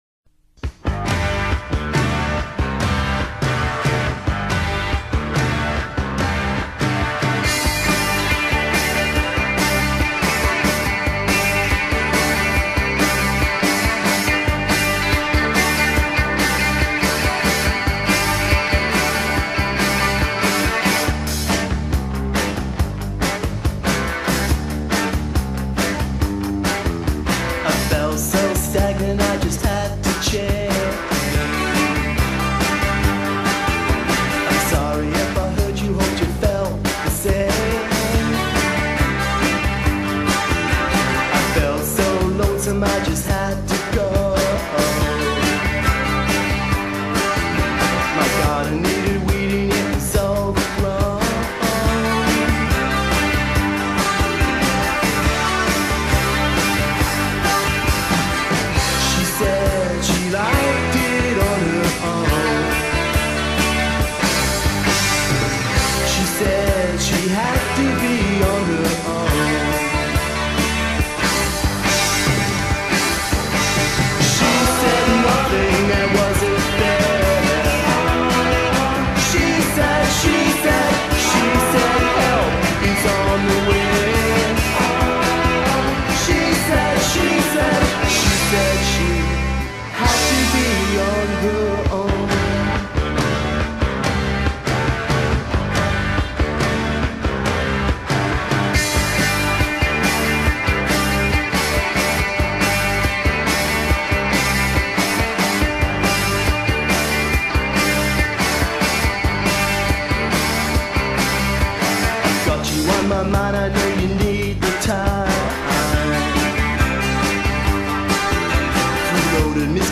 We recorded four songs at home on the eight-track.